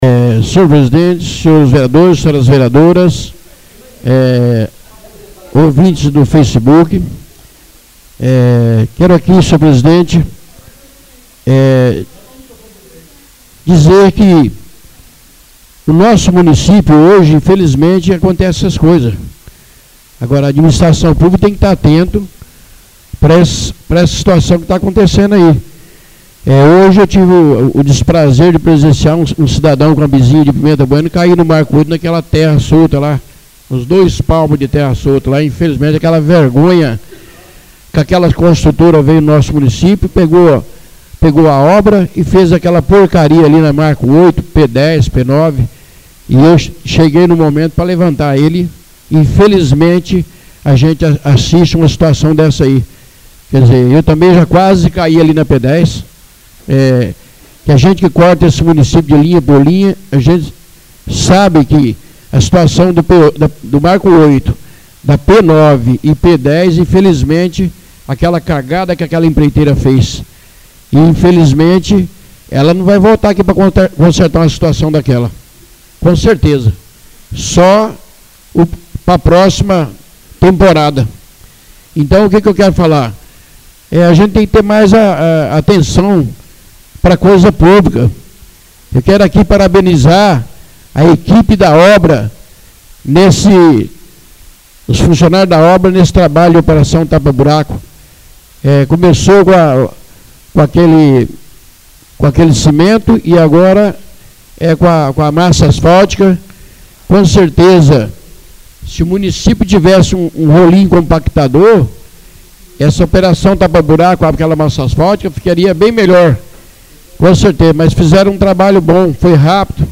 Oradores das Explicações Pessoais (26ª Ordinária da 3ª Sessão Legislativa da 6ª Legislatura)